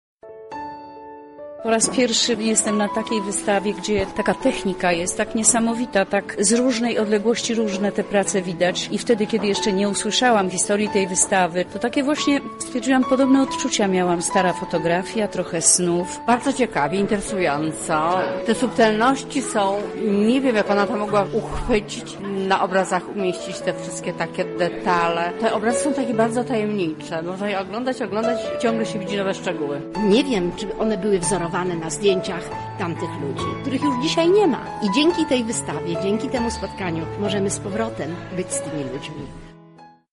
Na miejscu była nasza reporterka: